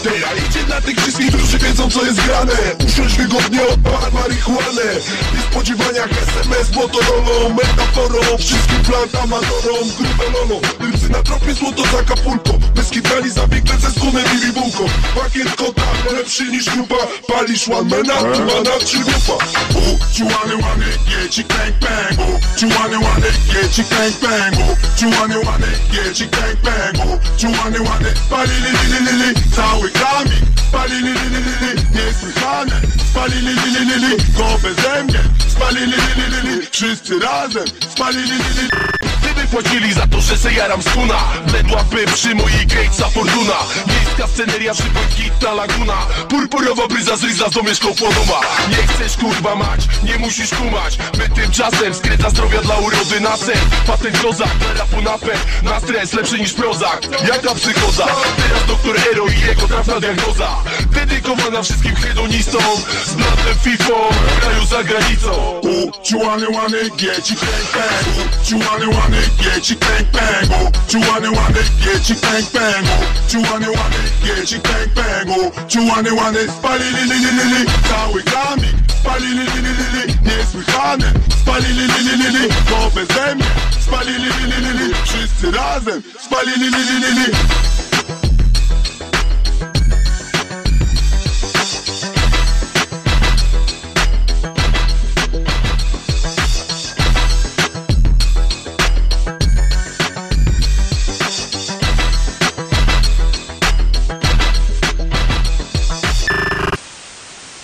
Applause.mp3